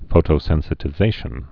(fōtō-sĕnsĭ-tĭ-zāshən)